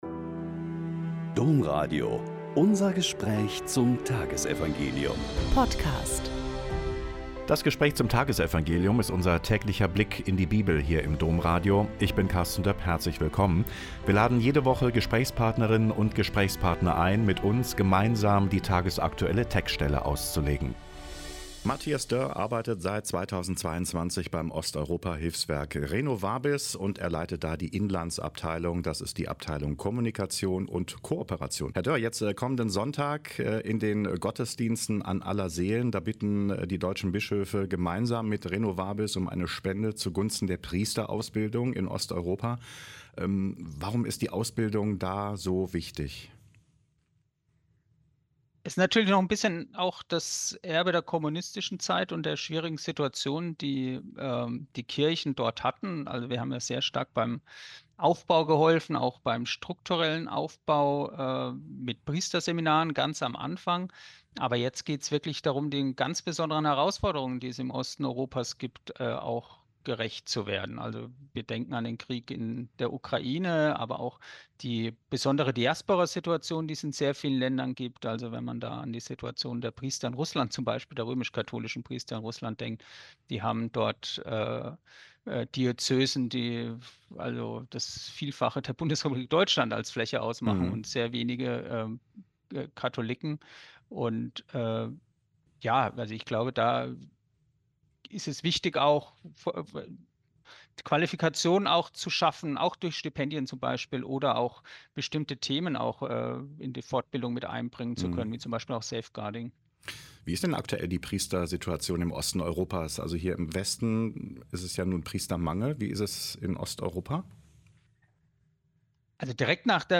Lk 13,31-35 - Gespräch